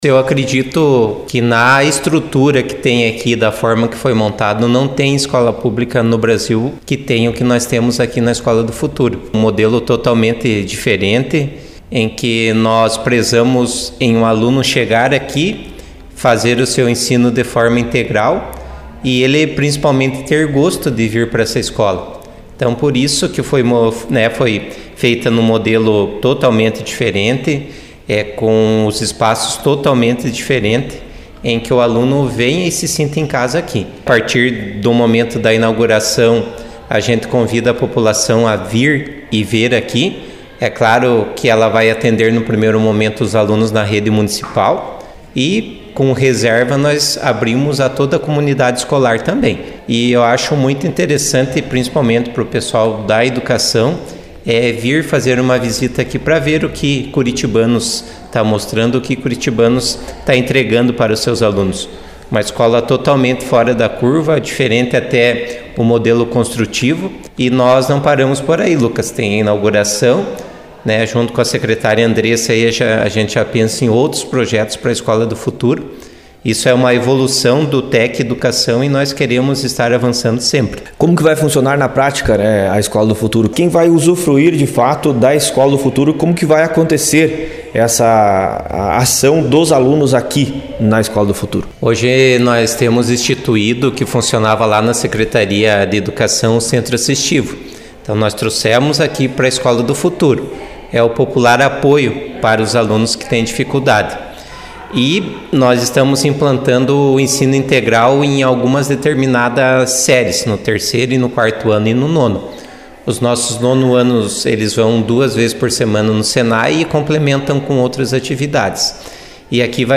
Prefeito Kleberson Luciano Lima falou da expectativa deste momento que considera um marco para a educação curitibanense.